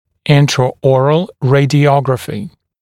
[ˌɪntrə»ɔːrəl ˌreɪdɪ’ɔgrəfɪ][ˌинтрэ’о:рэл ˌрэйди’огрэфи]внутриротовое рентгеновское исследование